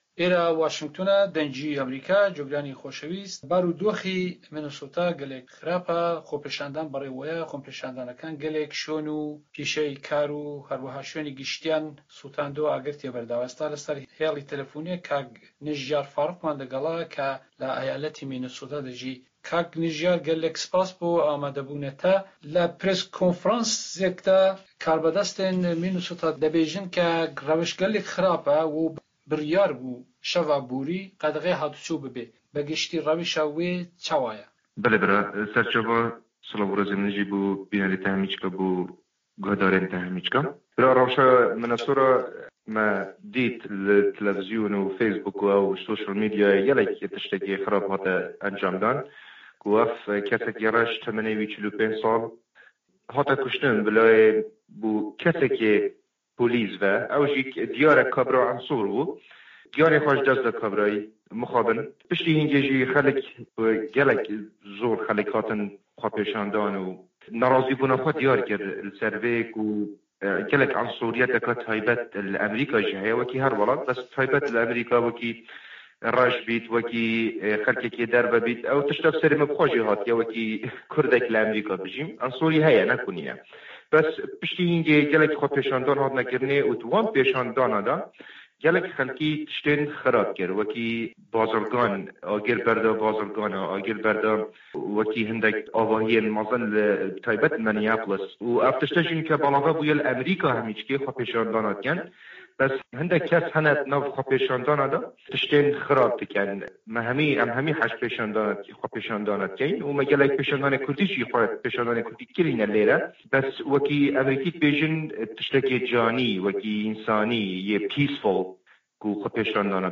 ئه‌مه‌ریکا - گفتوگۆکان